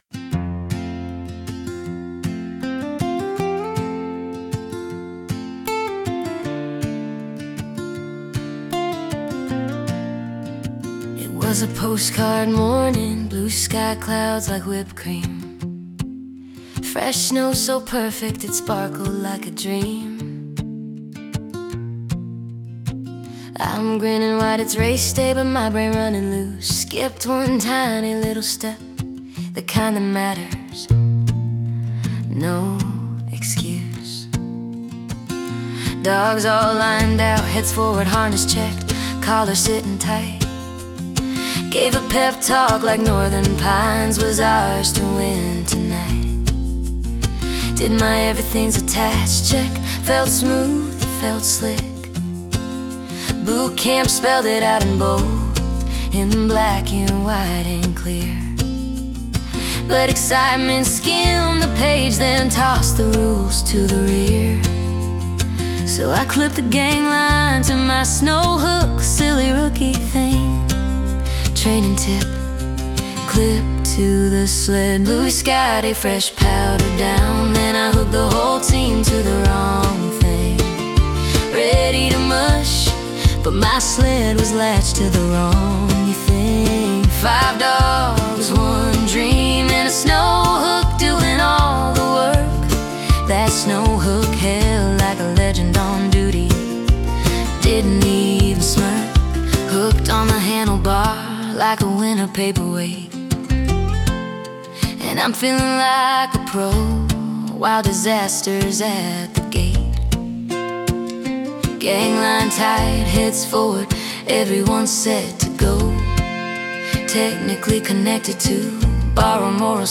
We’ve created this special collection of AI-generated songs to further enrich the stories shared here.